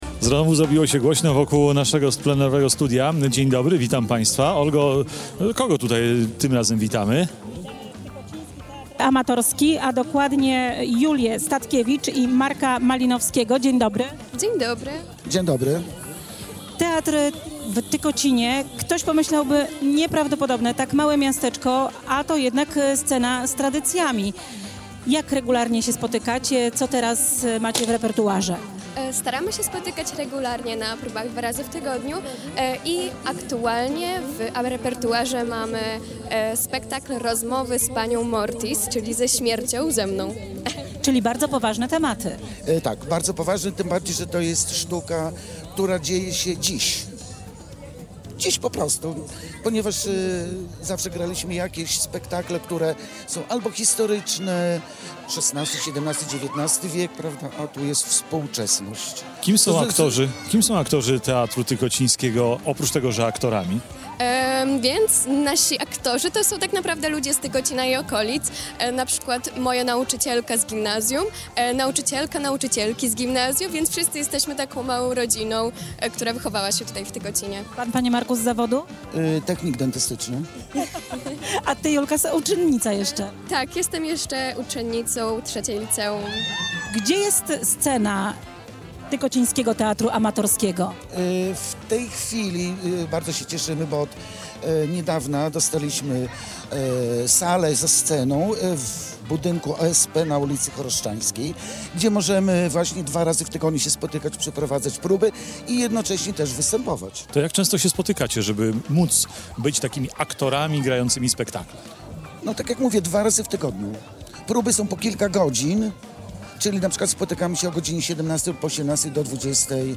Nasz program nadawaliśmy z malowniczego miasta nad Narwią od 13:00 do 17:00. Plenerowe studio rozstawiliśmy przy placu Czarnieckiego w Tykocinie.